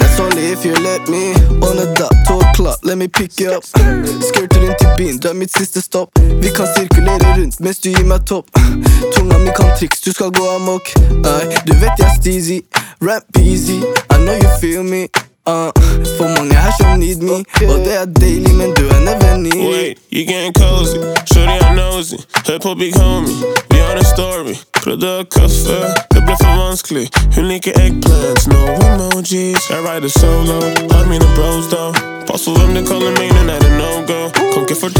# Doo Wop